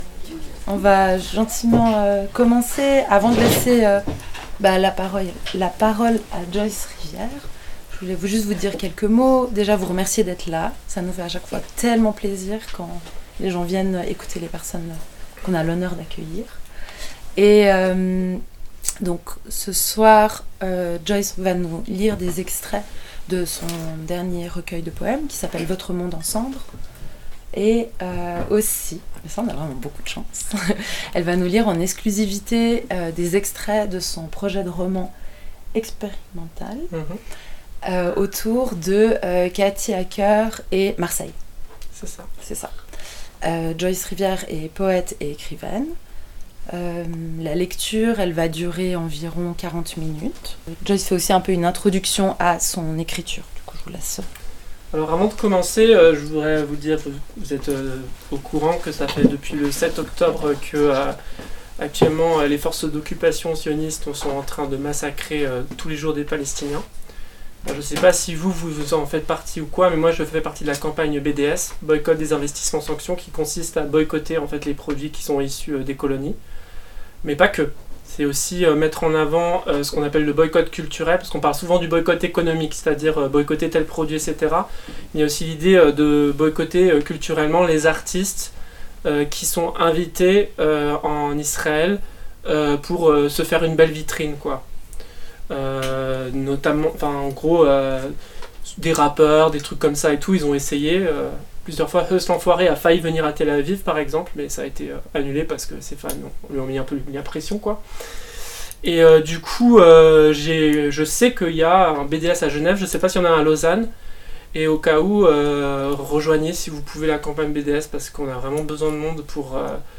lecture du recueil de poésie